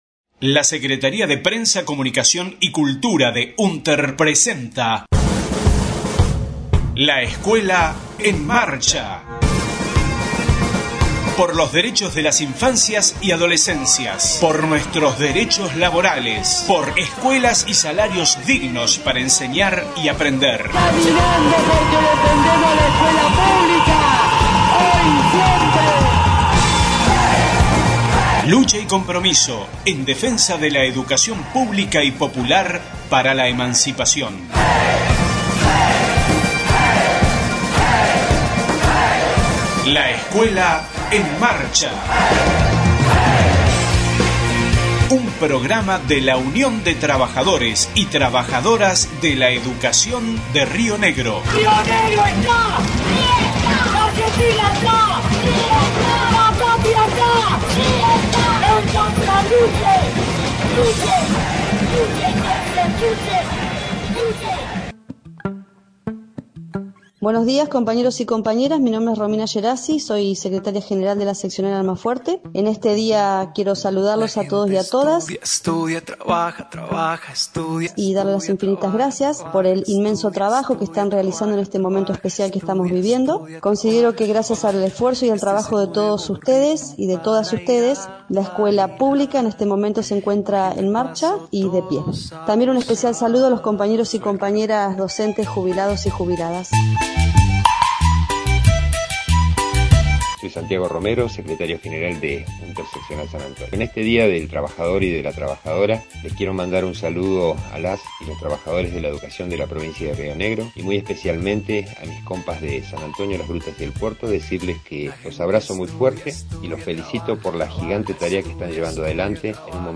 Saludo día internacional de lxs trabajadorxs, voces de Secretarixs Generalxs de Seccionales.